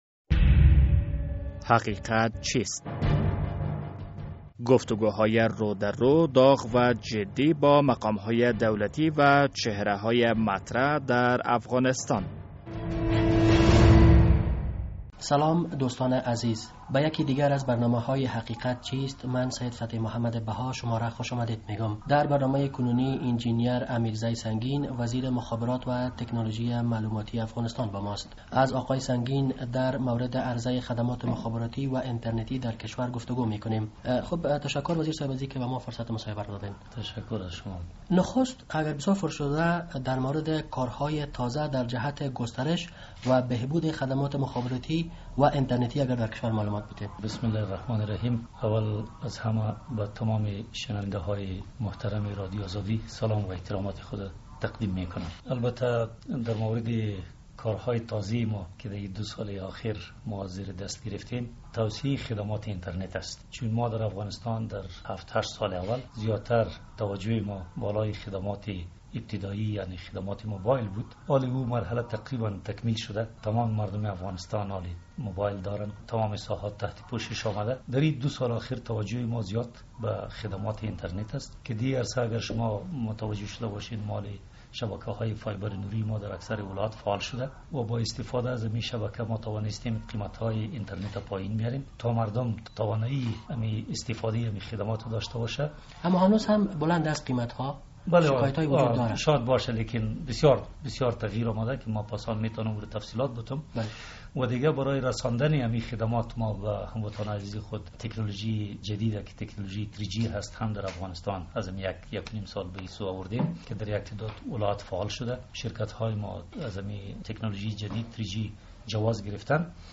مهمان این هفته برنامهء حقیقت چیست، انجنیر امیرزی سنگین وزیر مخابرات و تکنالوژی معلوماتی افغانستان است.